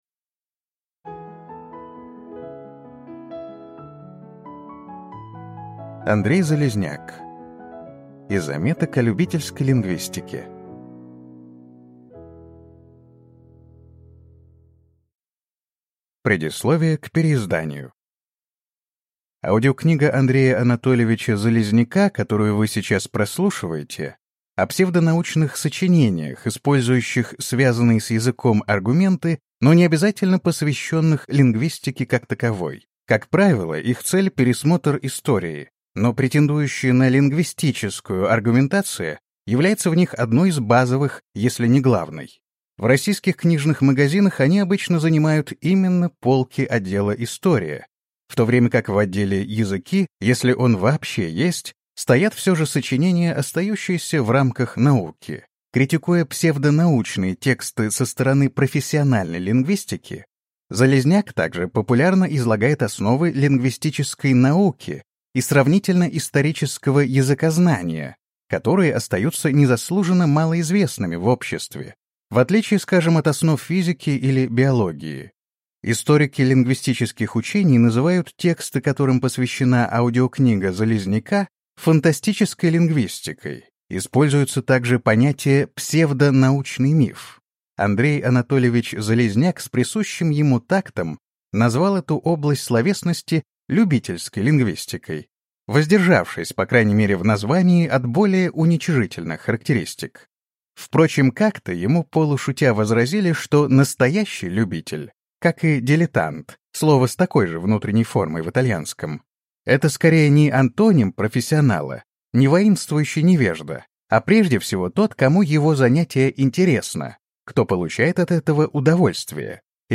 Аудиокнига Из заметок о любительской лингвистике | Библиотека аудиокниг